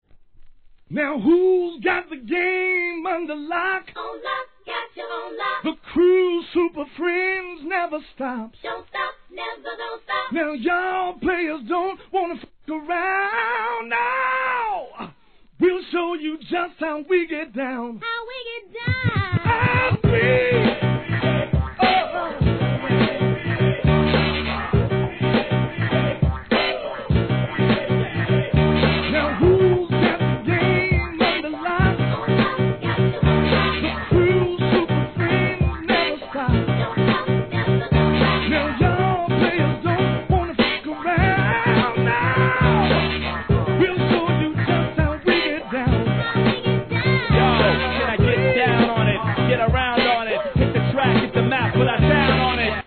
HIP HOP/R&B
バックコーラスが映える